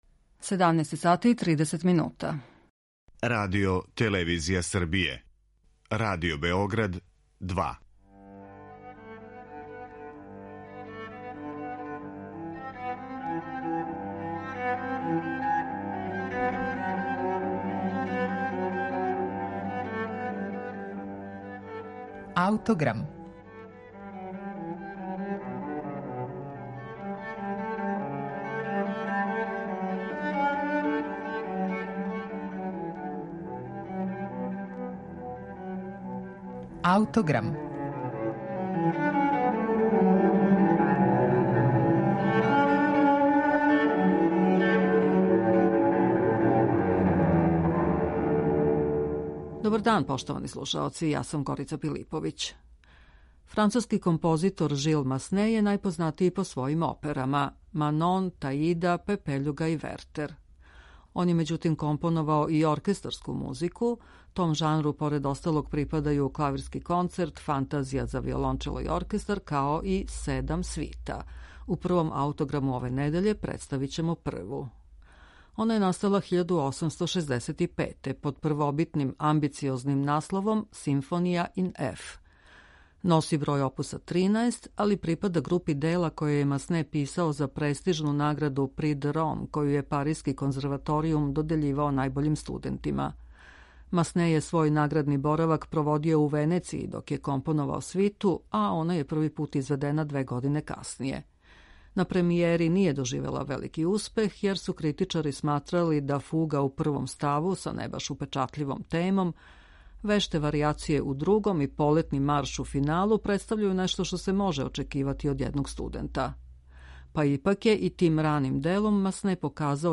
Он је међутим, компоновао и оркестарску музику.
На премијери није доживела велики успех јер су критичари сматрали да фуга у I ставу, са не баш упечатљивом темом, веште варијације у II и полетни марш у финалу, представљају нешто што се може очекивати од једног студента.